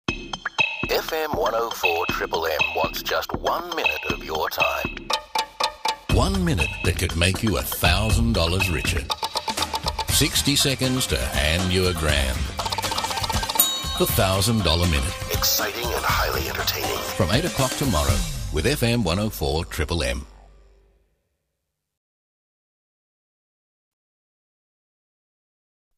Promos
Rode NT-1A Microphone, Focusrite interface.
BaritoneDeepLow